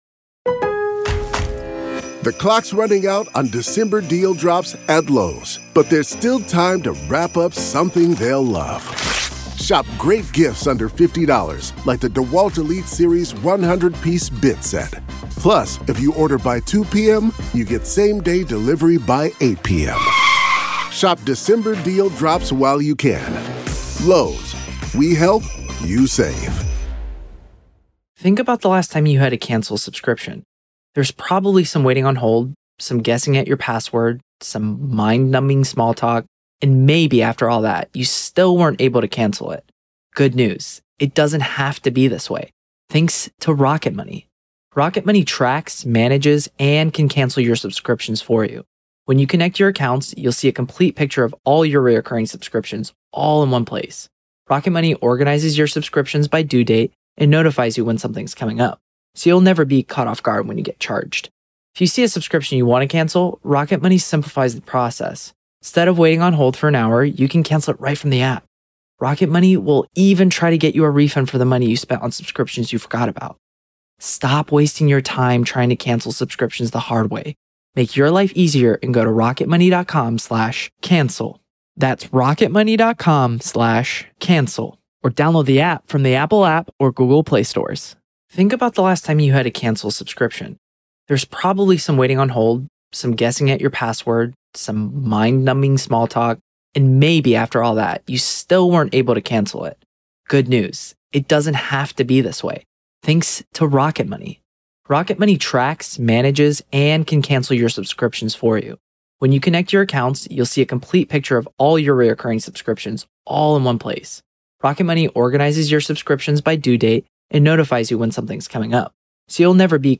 True Crime News & Commentary